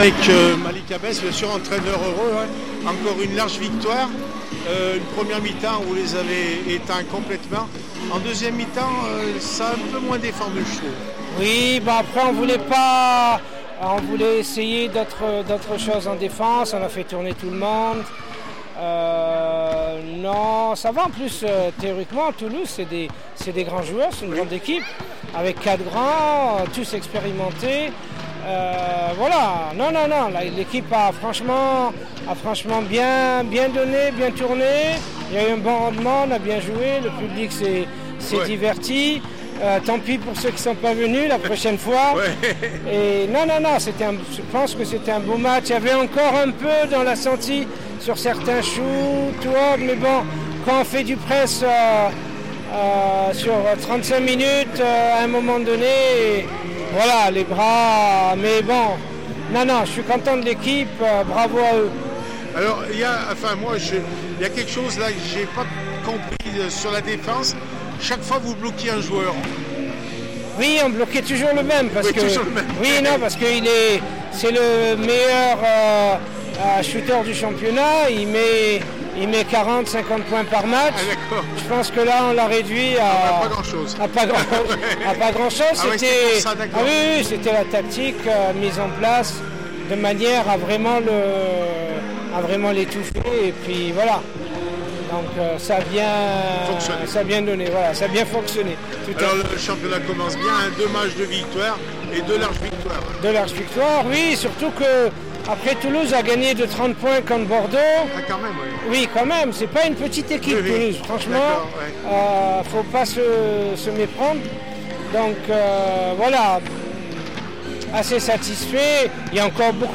5 octobre 2020   1 - Sport, 1 - Vos interviews, 2 - Infos en Bref   No comments